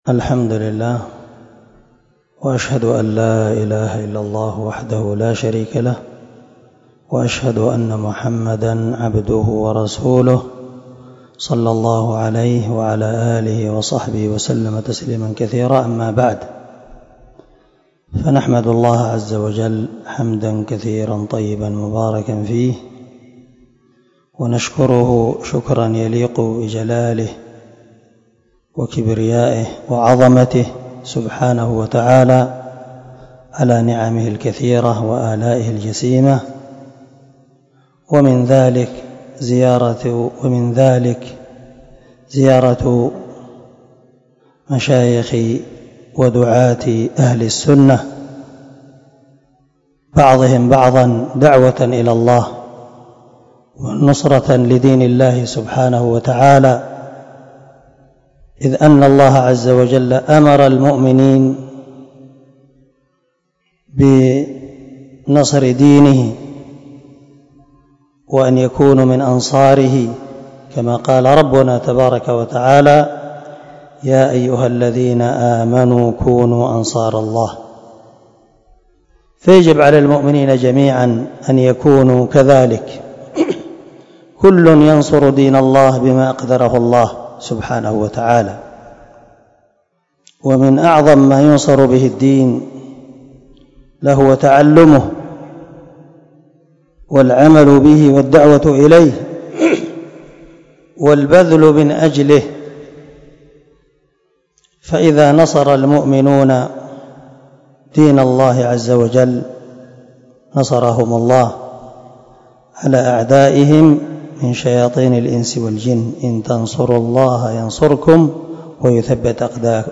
كلمة ترحيب